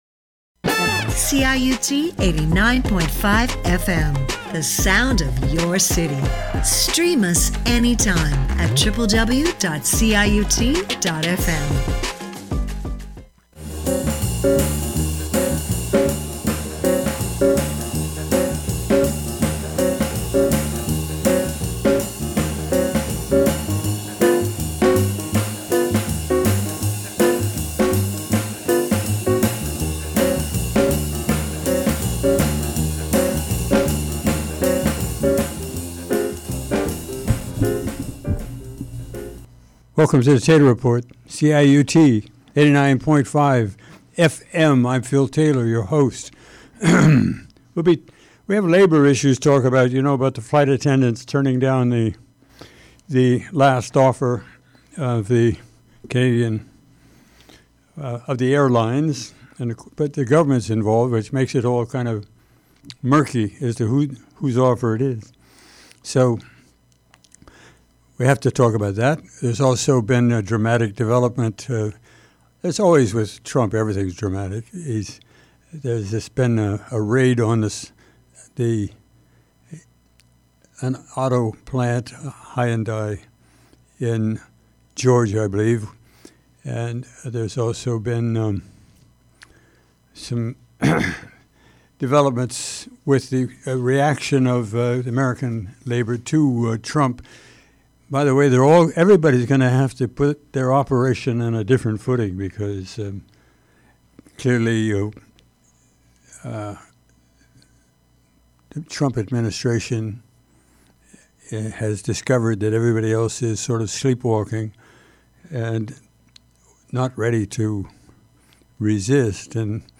< Version: 1 Taylor Report commentary Version Description: Click on the Red Circle with the White Arrow in order to Listen to the Commentary Version Length: 12:21 a.m. Date Recorded: Sept. 8, 2025 1: 12:21 a.m. - MB download